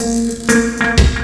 FXLOOP 02 -R.wav